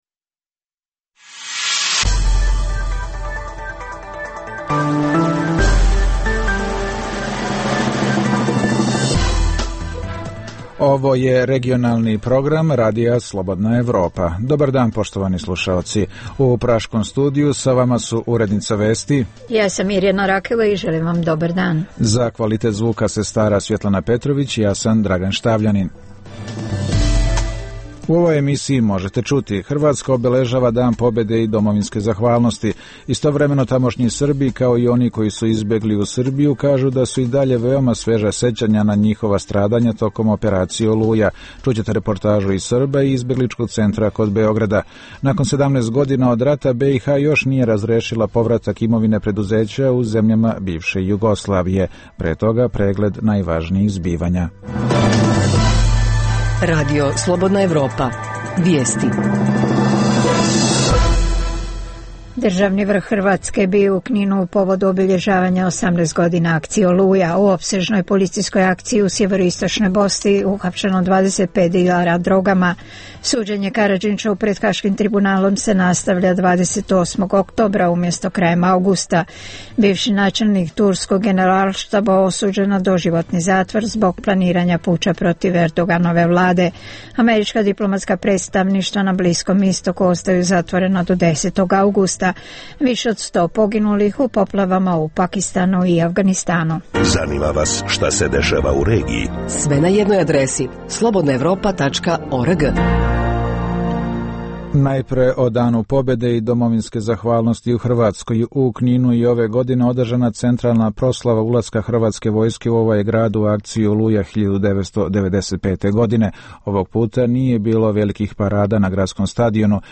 Čućete reportažu iz Srba i izbegličkog centra kod Beograda.